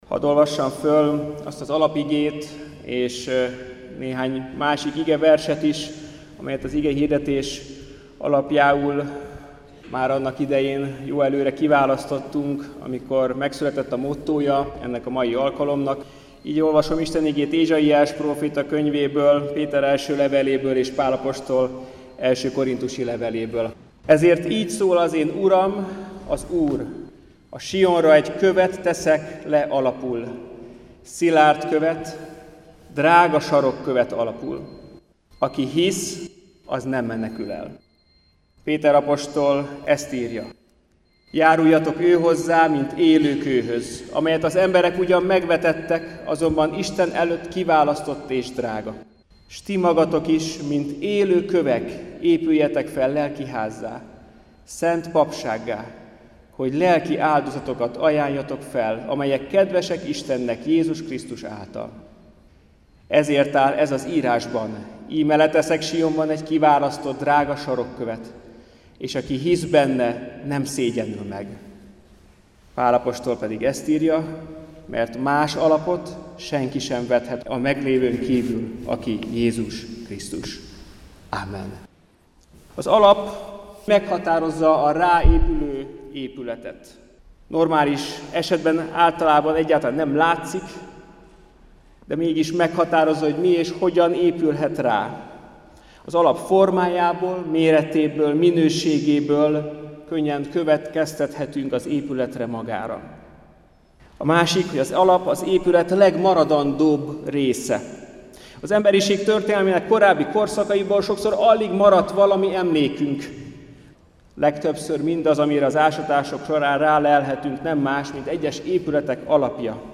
Az októberi országos evangelizáción